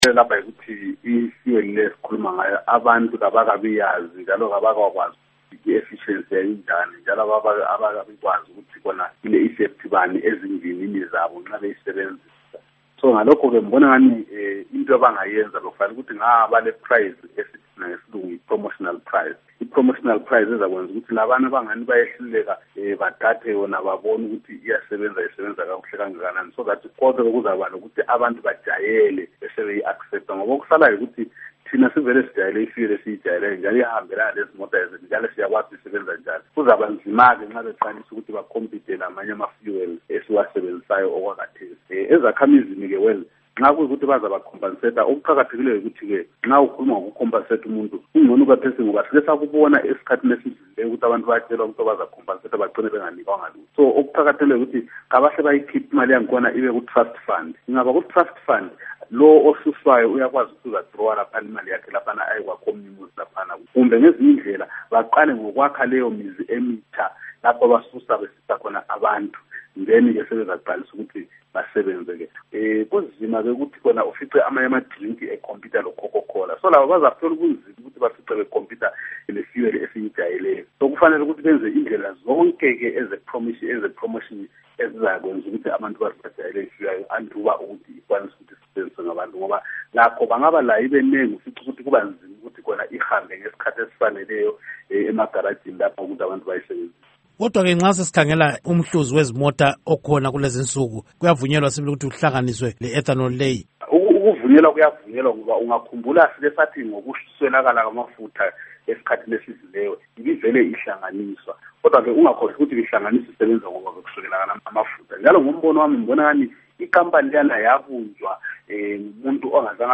Ingxoxo Esiyenze LoMnu. Abednico Bhebhe